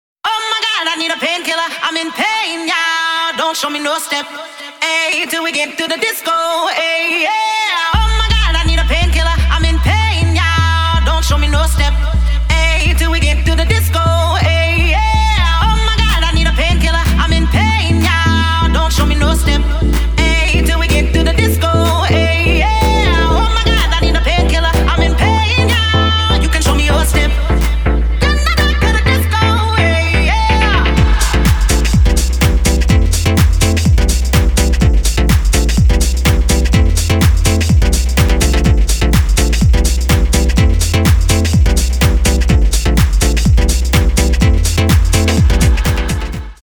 • Качество: 320, Stereo
громкие
женский вокал
dance
Electronic
club
Funky House
Jackin House
Groove House